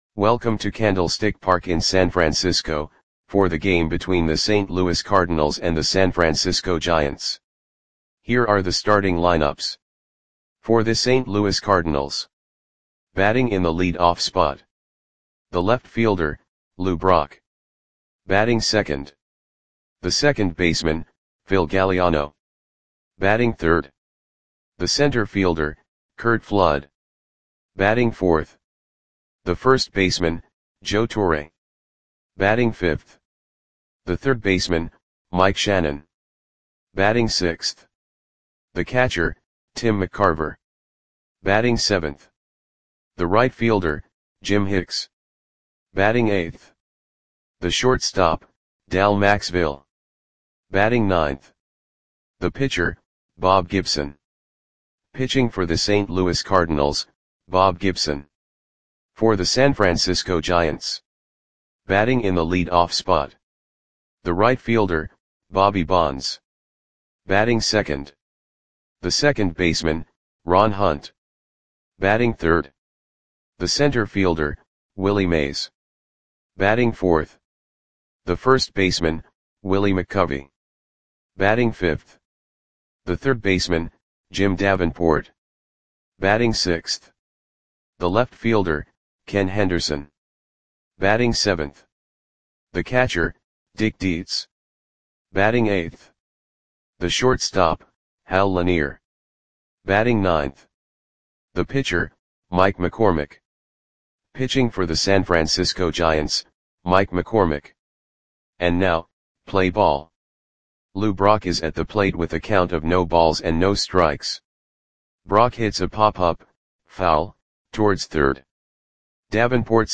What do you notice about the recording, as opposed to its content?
Lineups for the San Francisco Giants versus St. Louis Cardinals baseball game on May 20, 1969 at Candlestick Park (San Francisco, CA).